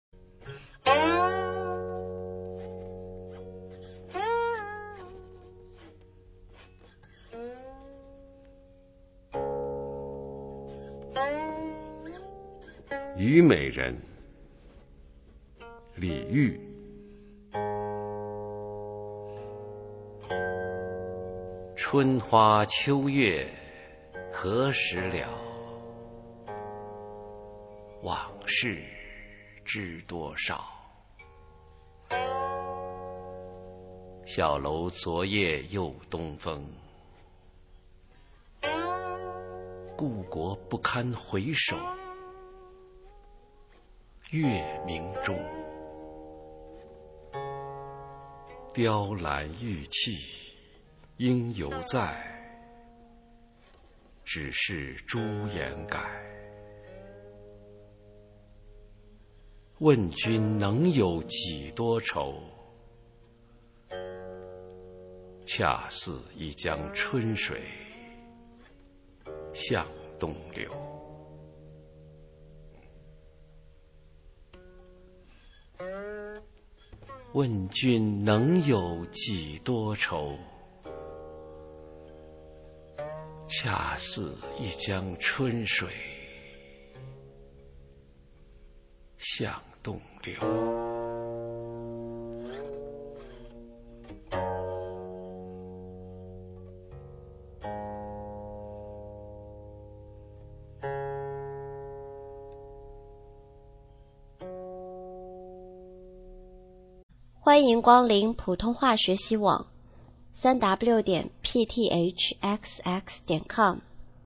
首页 视听 学说普通话 美声欣赏